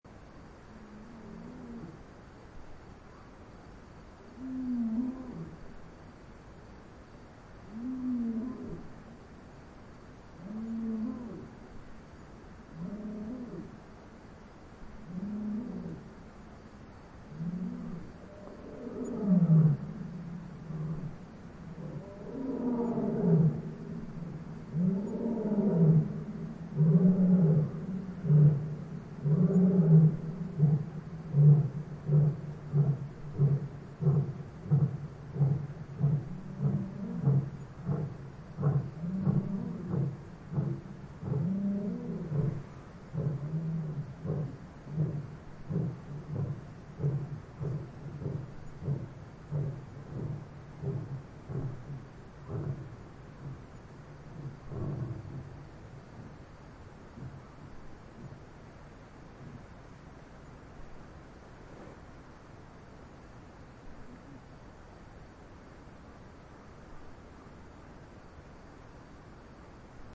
Lions In Gonarezhou Botão de Som